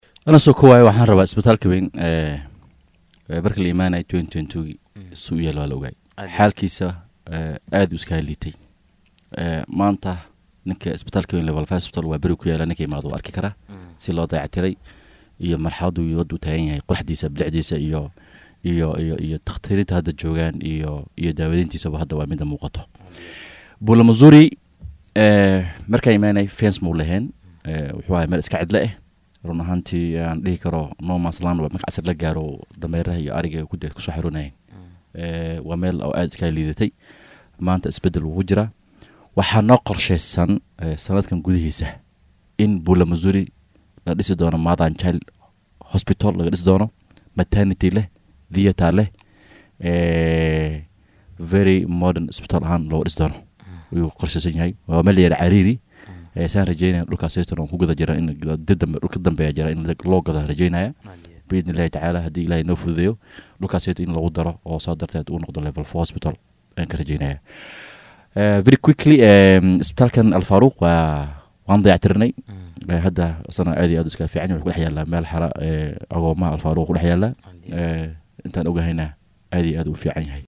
Wakiilka laga soo doortay waaxda Waaberi ee Magaalada Garissa Abubakar Xaaji Sugow oo saaka marti inoogu ahaa Barnaamijka Hoggaanka Star ayaa ka warbixiyay waxyaabo badan oo ku saabsan Hormarka Waaberi.